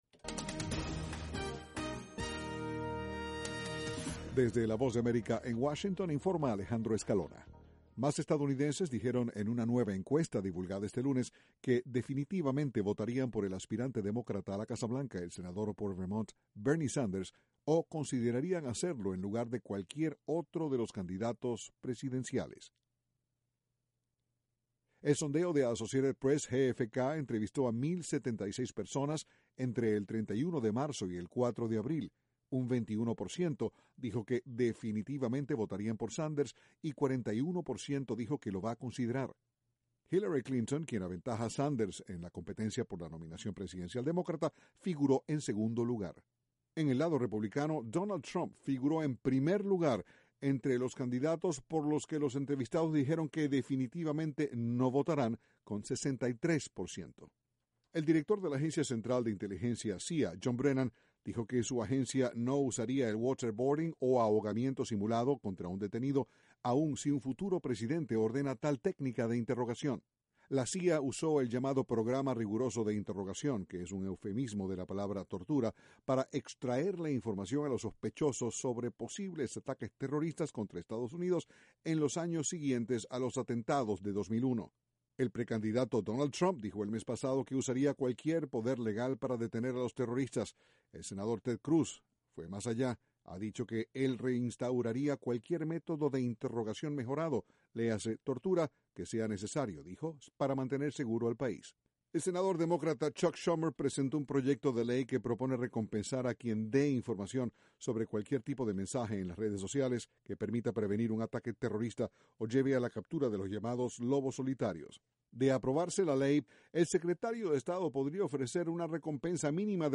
VOA: Noticias de la Voz de América, Washington